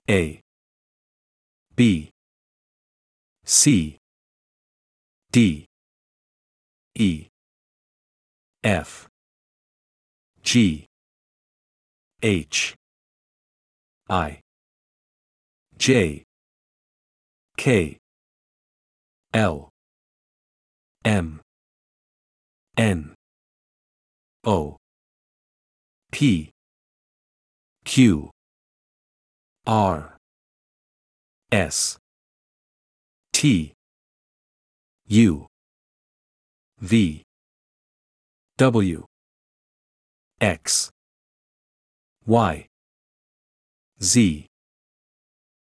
In this lesson we will teach you the American pronunciation of the individual letters!
Male Speaker
Learn-English-Online-The-English-Alphabet-ABC-Pronunciation-male.mp3